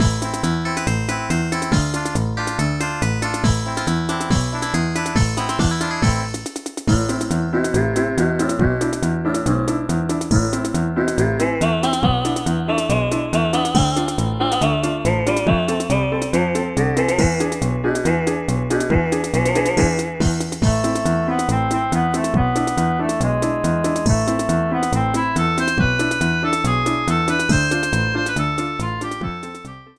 Clipped to 30 seconds and applied fade-out